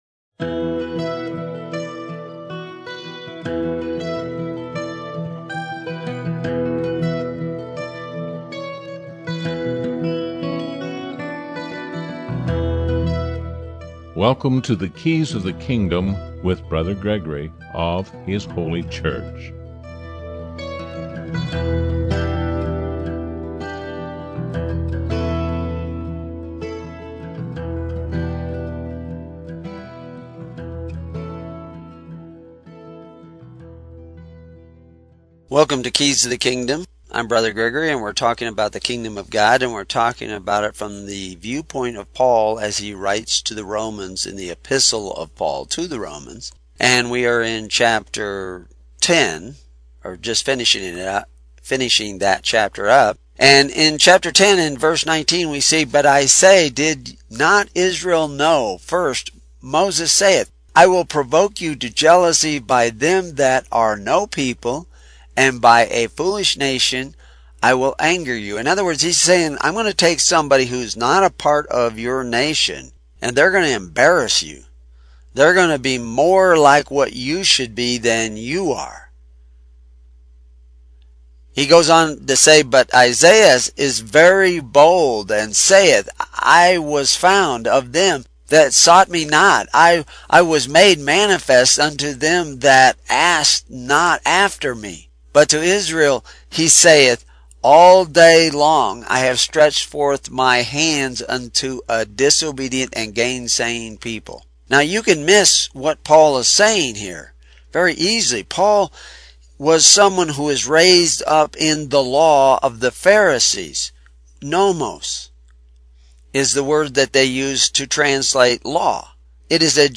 Audio study from Romans 10 to Romans 13